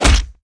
SE_Hit.mp3